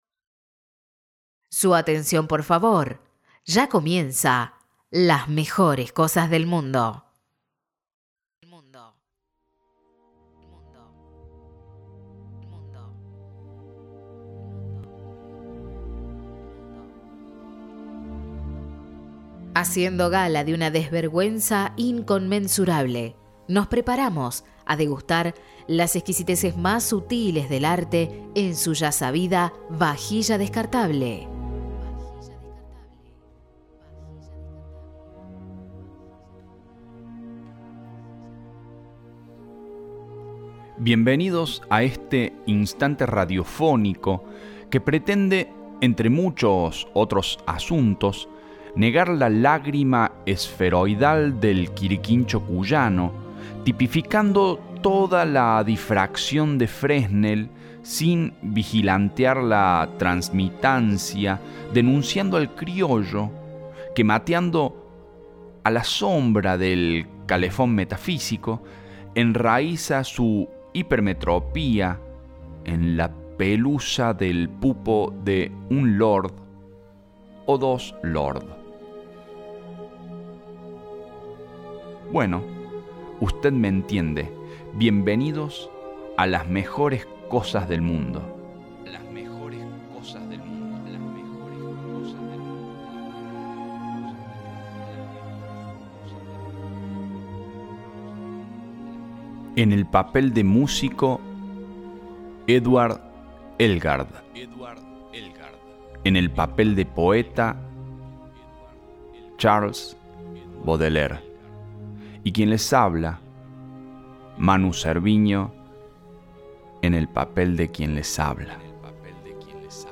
02 – Las flores del mal (Charles Baudelaire) con música de Edward Elgar Un capítulo nostálgico y lento en el que leemos algunos de los más conmovedores poemas de esta monumental pieza poética y escuchamos al querido Edward Elgar